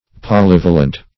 Polyvalent \Po*lyv"a*lent\, a. [Poly- + L. valens, p. pr.